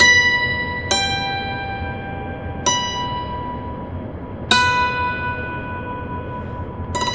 Dulcimer01_134_G.wav